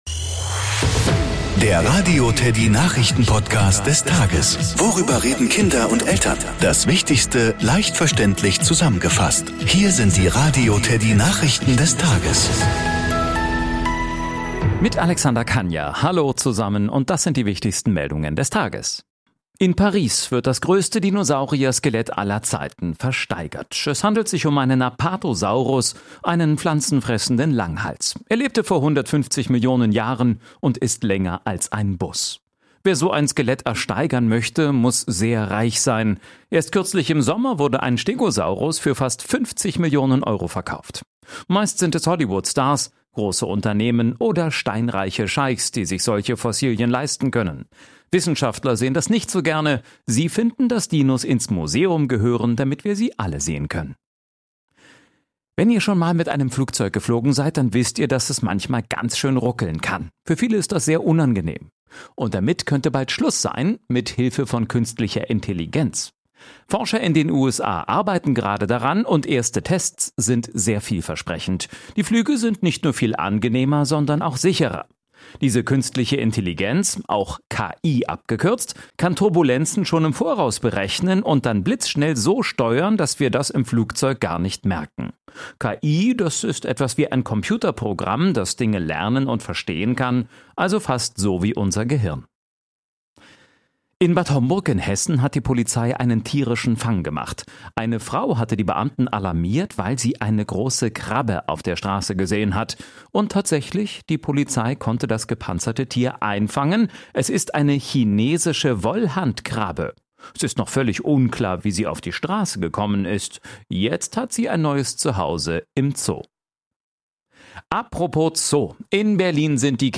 Nachrichten , Kinder & Familie